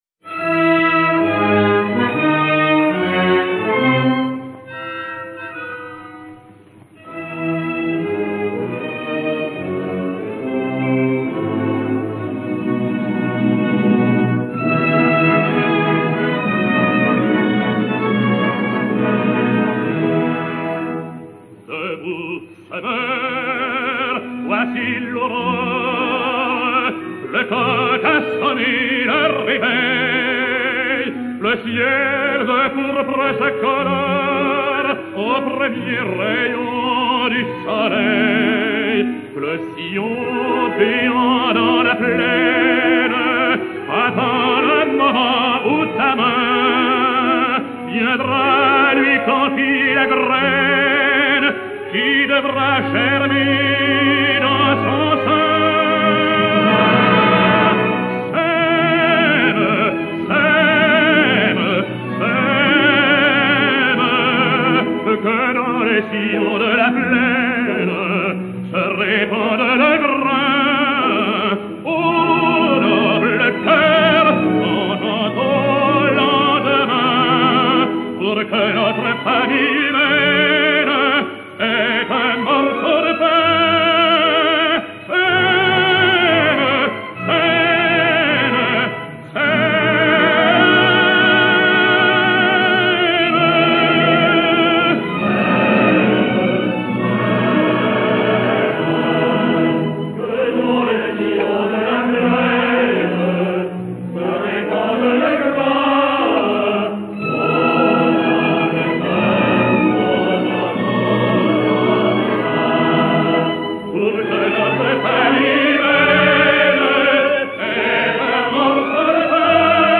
chanson (par.